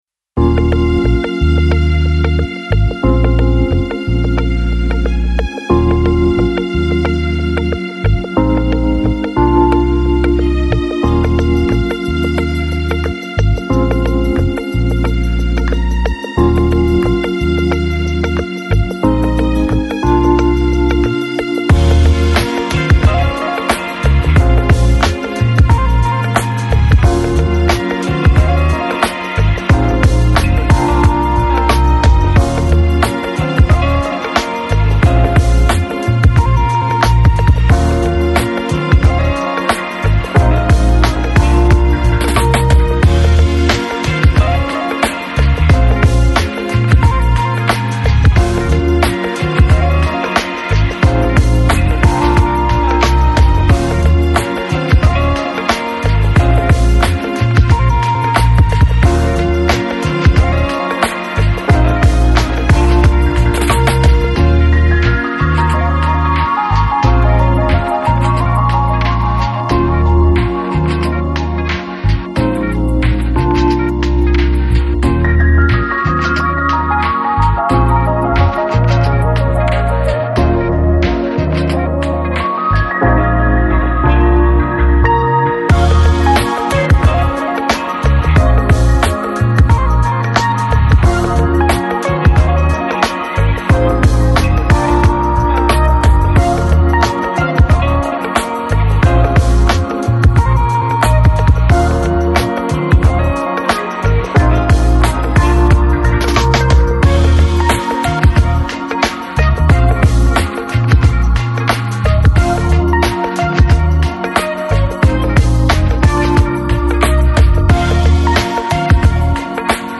Жанр: Balearic, Downtempo, Lounge